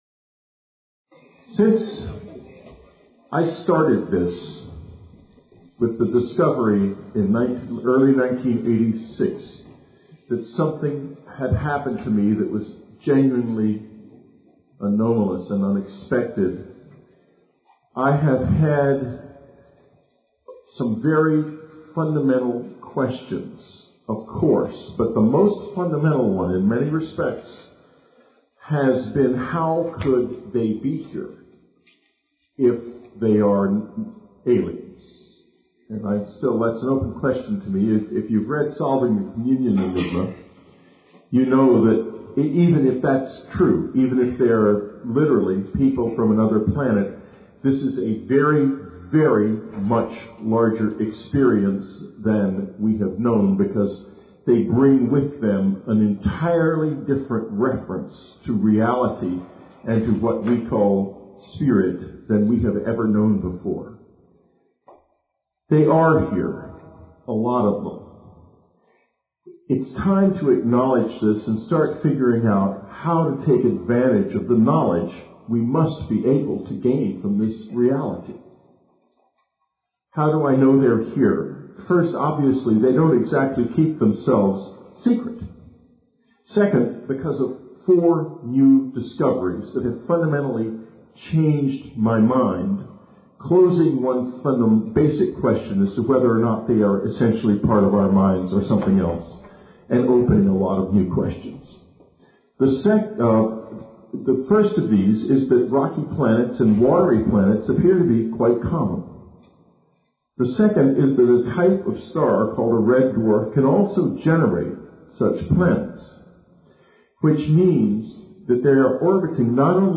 Listen as Whitley reproduces this marvelous mediation, and you, also, can experience its great power and deep peace.